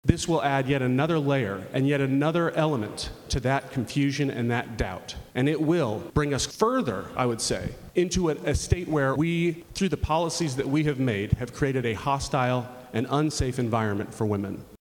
CLICK HERE to listen to commentary from Representative Forrest Bennett.
At the Oklahoma Capitol, there was a heated debate on the house floor after a bill was proposed that makes the trafficking of abortion drugs a felony offense. Representative Forrest Bennett spoke out against the bill.